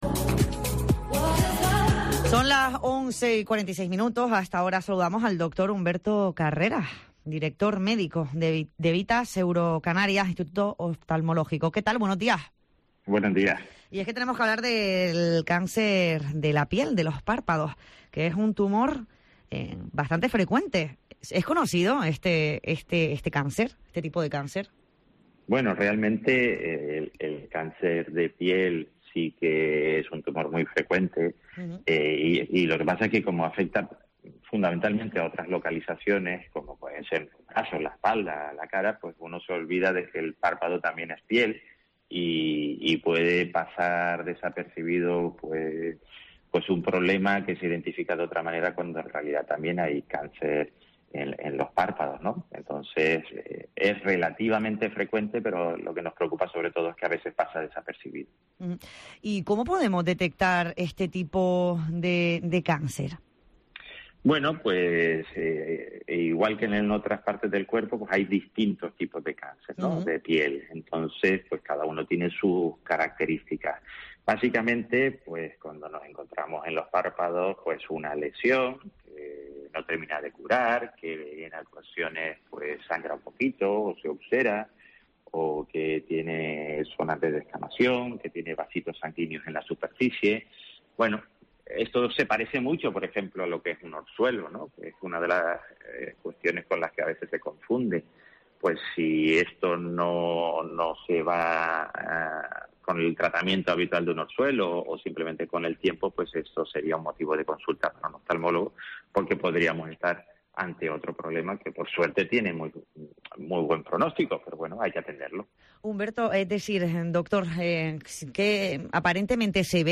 ha asegurado en los micrófonos de COPE Gran Canaria